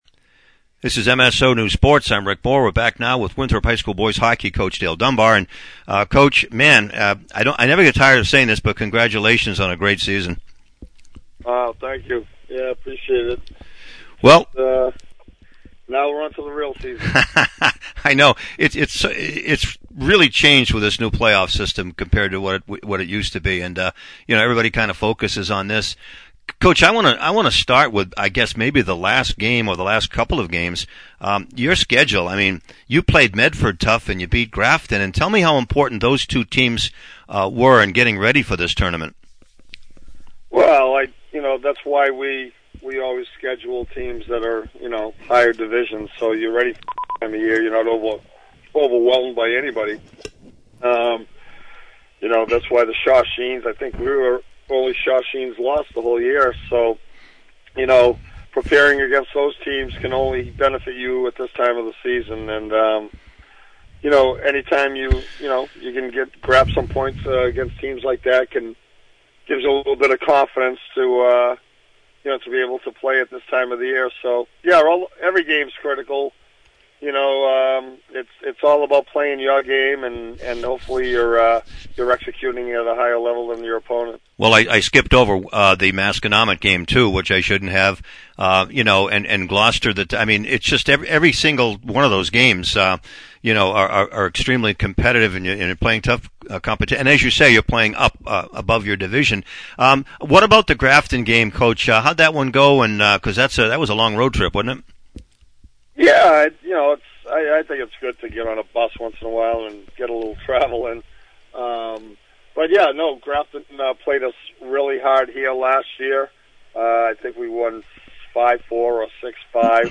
(Audio) Post-game, Pre-game